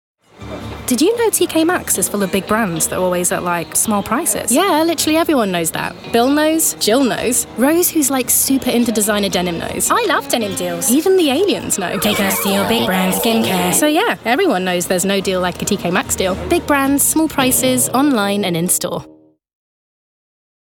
Versatile professional voiceover and actor with an RP/neutral English accent and an engaging, friendly and clear voice. Excels at natural, conversational corporate reads and character work. Broadcast-quality studio with rapid turnaround of finished audio available.